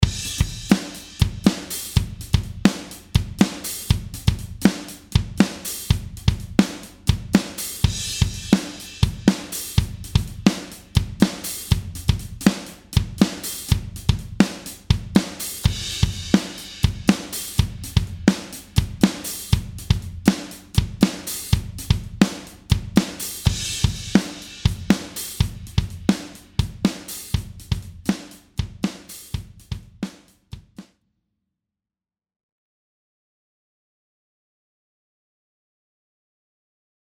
Attachments Drums.mp3 Drums.mp3 1.1 MB · Views: 117